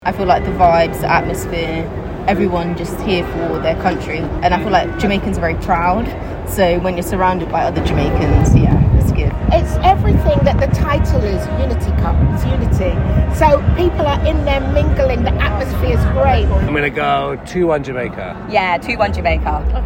Spectators at the Unity Cup Final, Brentford